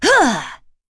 Seria-Vox_Landing.wav